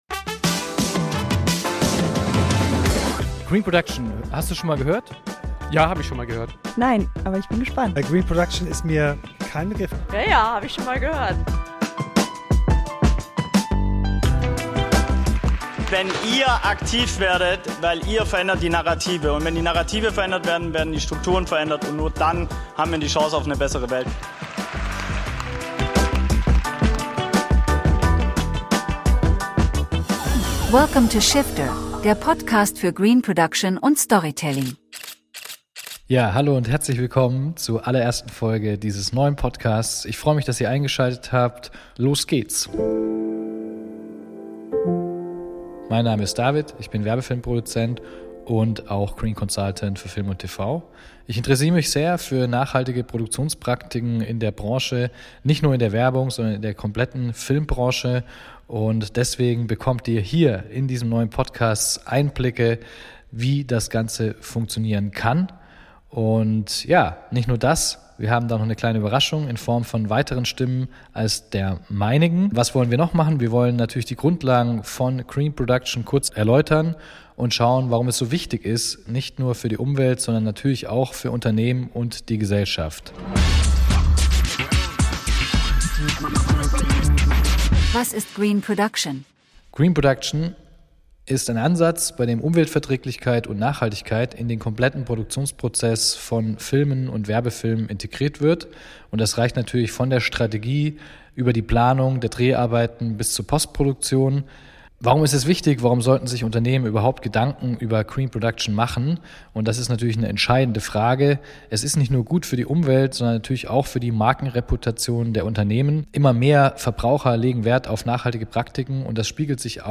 *Stimmen vom Bock auf Morgen Festival 2023.